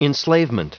Prononciation du mot enslavement en anglais (fichier audio)
Prononciation du mot : enslavement